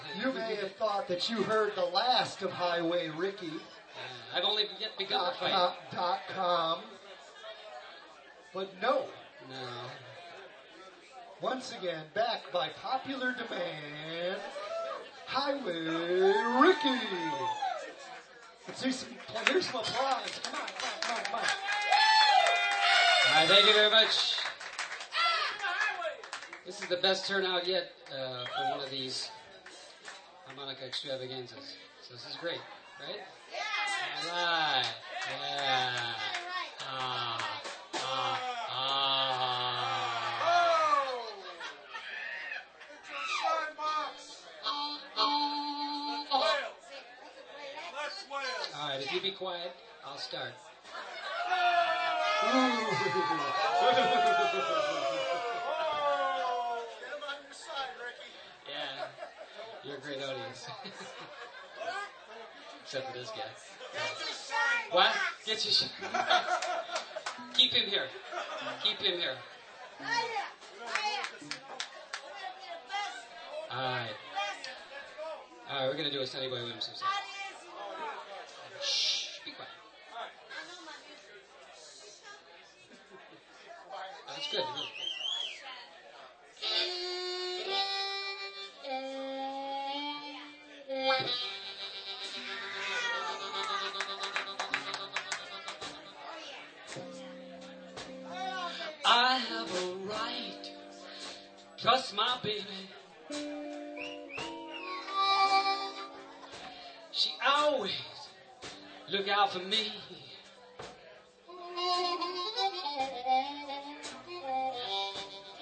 5th OTS Recital - Winter 2005 - rjt_4234